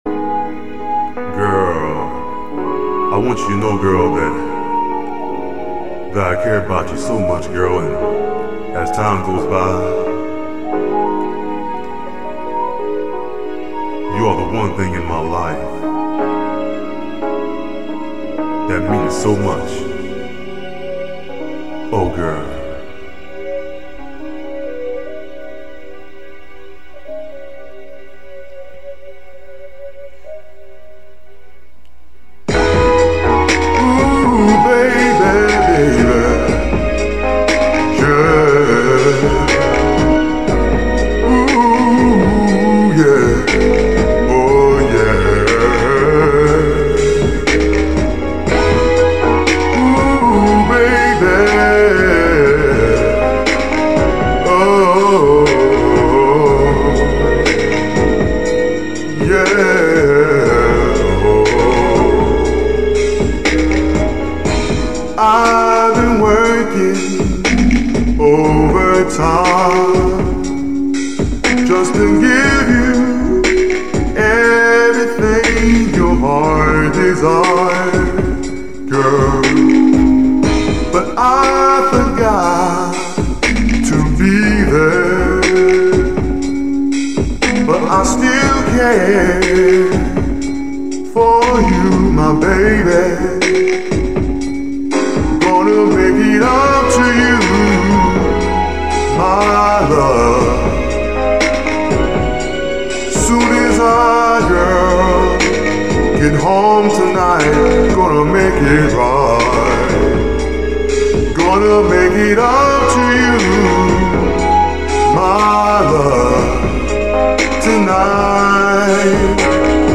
MUSIC ROMANCE LOVE